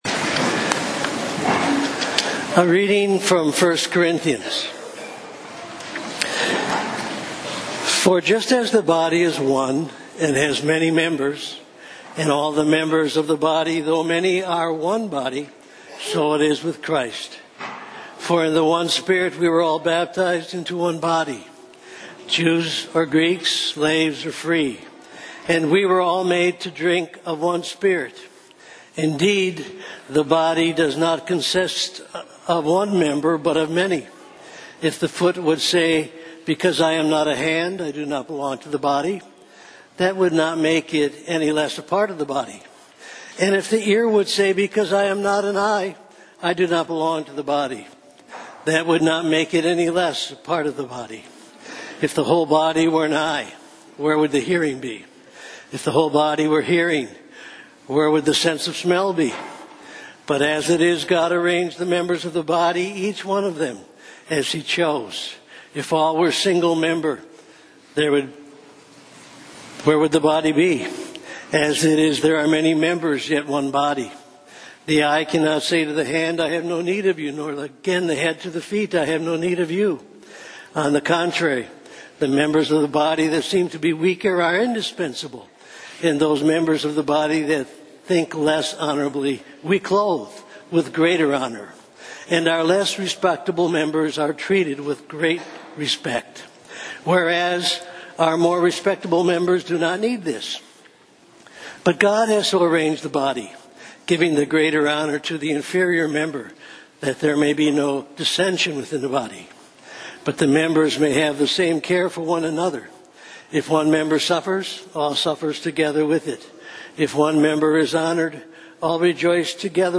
SERMON ARCHIVE
January 27, 2019 – Third Sunday after Epiphany – 9:30 am